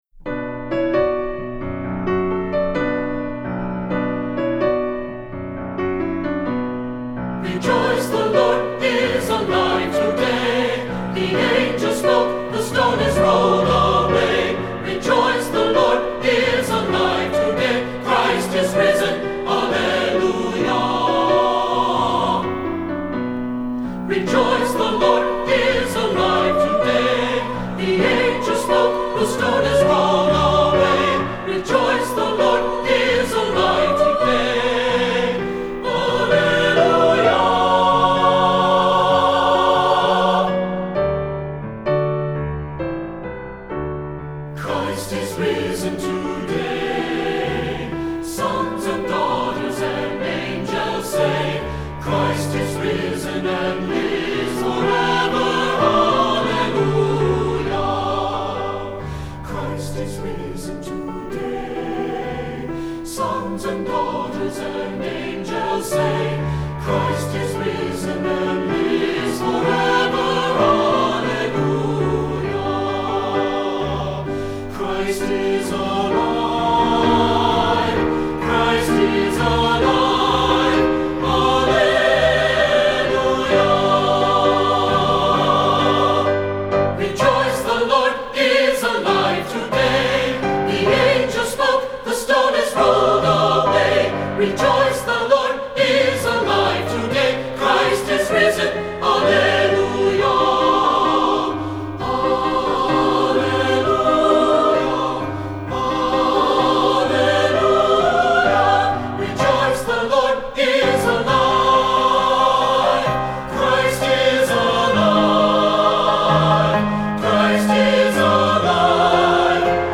Voicing: SAT